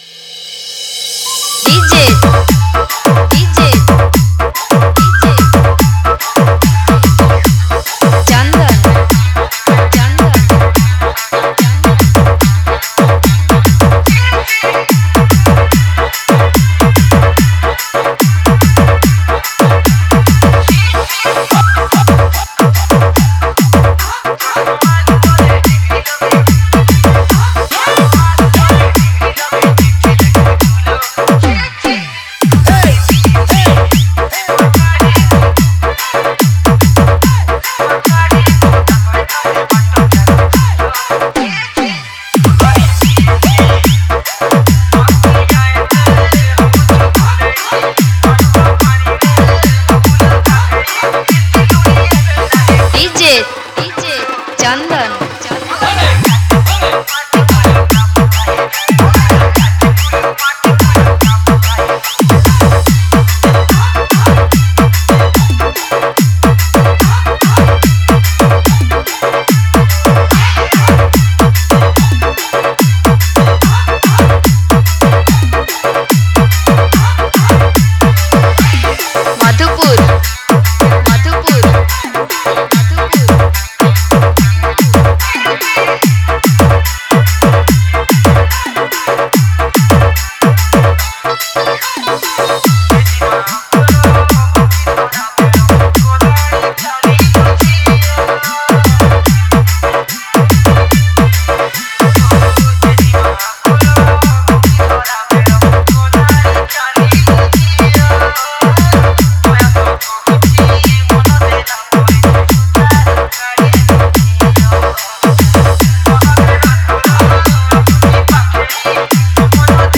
Category: New Year Special DJ Song 2021